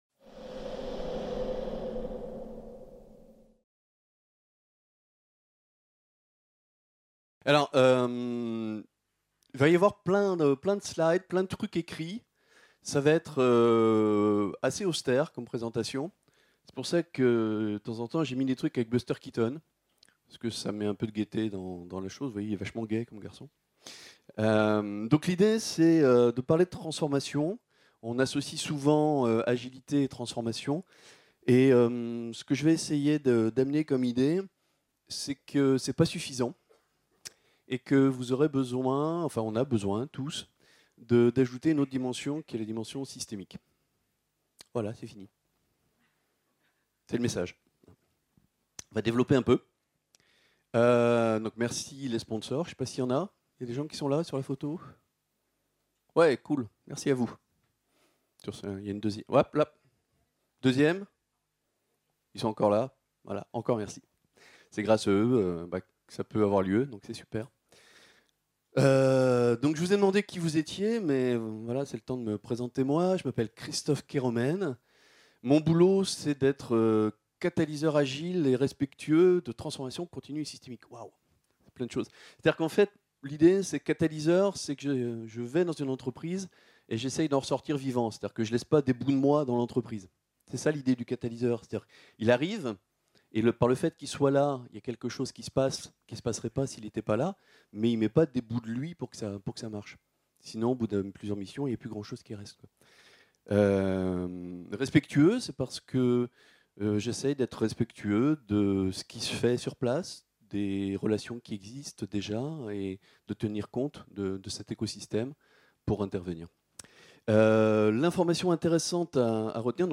PA2019 | 05 - Atelier 3 : Pour une stratégie de transformation agile (conf)